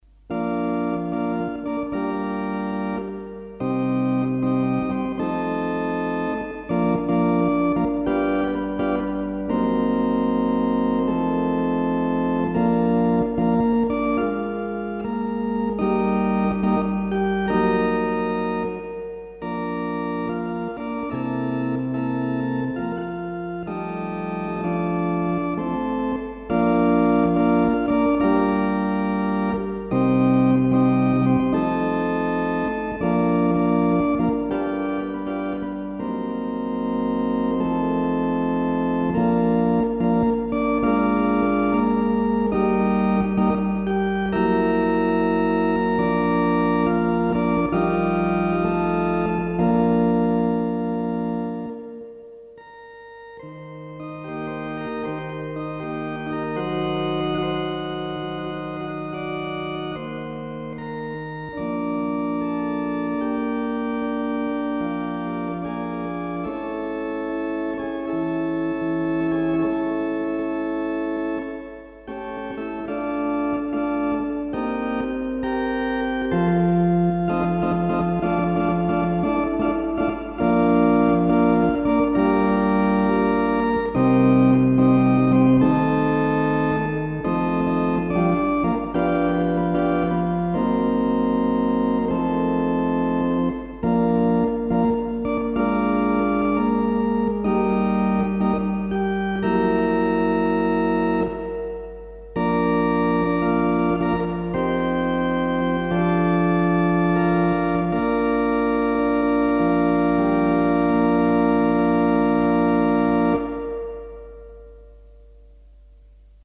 Philicorda
As the recording bug was already well installed (audio only in those days!) a few tape records were made – some of which have survived the intervening years and multiple shifts in technology and media standards.
philicorda_kh_classic.mp3